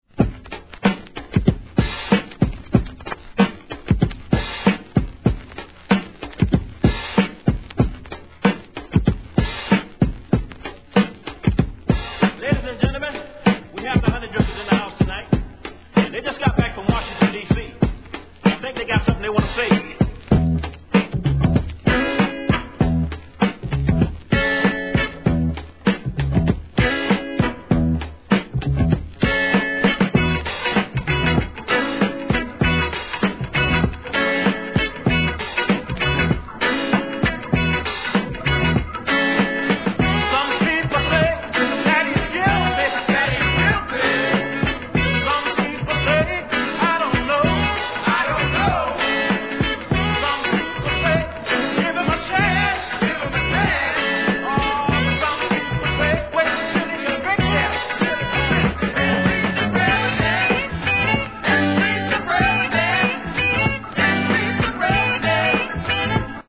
HIP HOP/R&B
'80s後半〜'90s初期HIP HOPにおいて定番のBREAK BEATS満載です!!